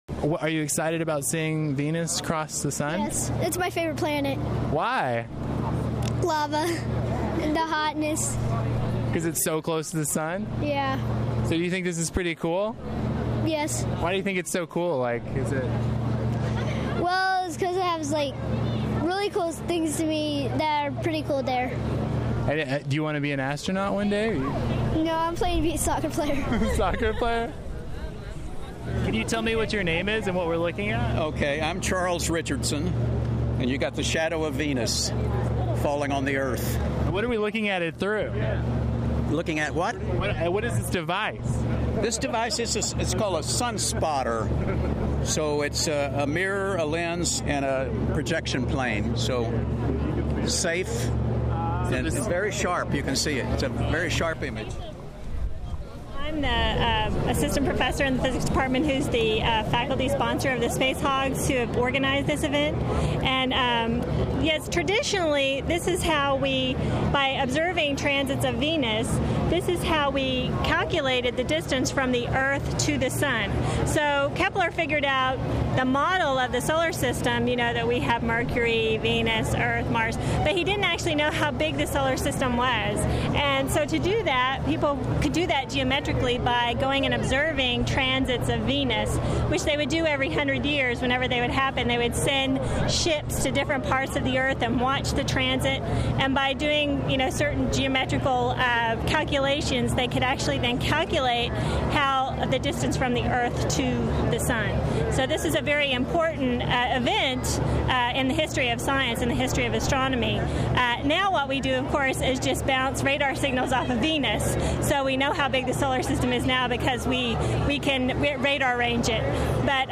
About 150 people gathered on the grass near the intersection of Knapp Drive and Gifford Avenue in Fayetteville Tuesday to watch the transit of Venus in front of the Sun.